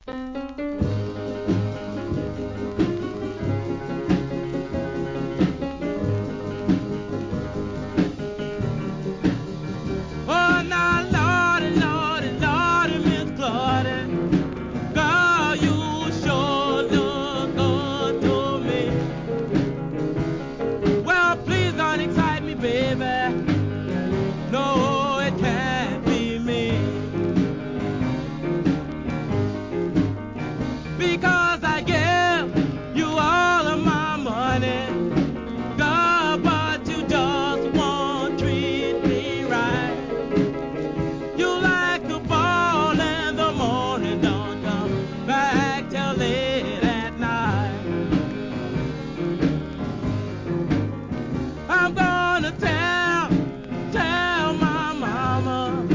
SOUL/FUNK/etc...
Rhythm & Blues